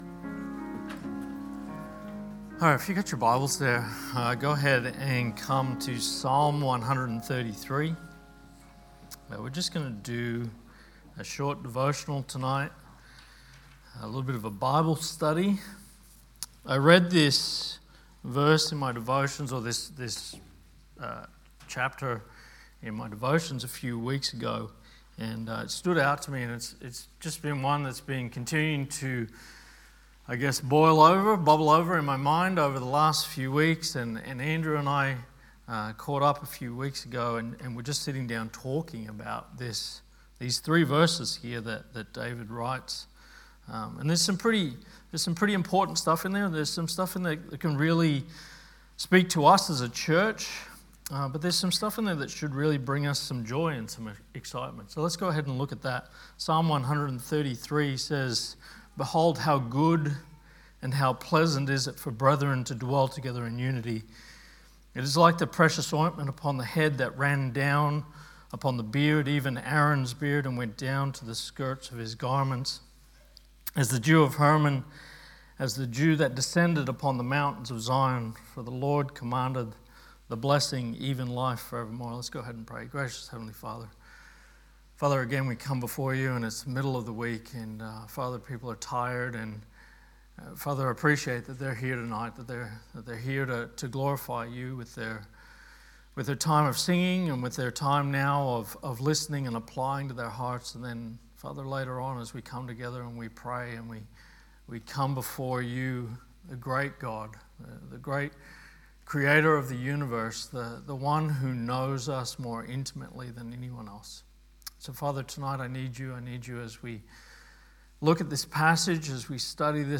Sermons | Good Shepherd Baptist Church